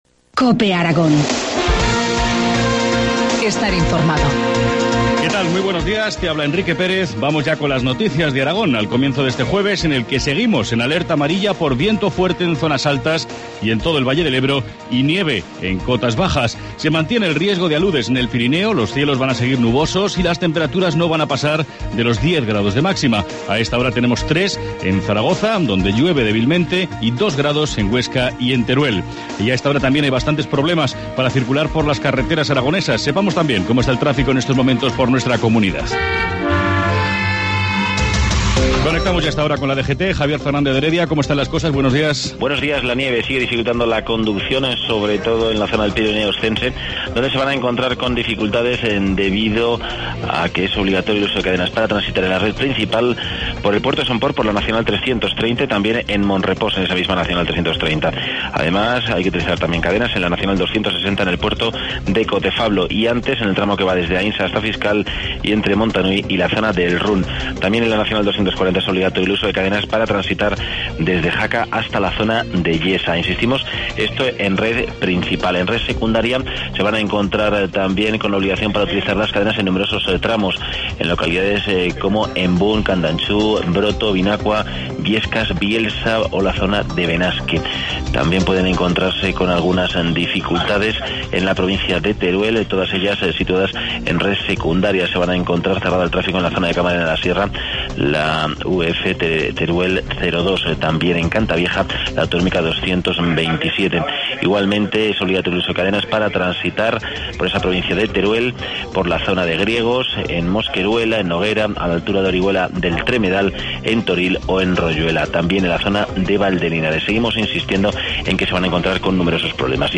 Informativo matinal, jueves 24 de enero, 7.25 horas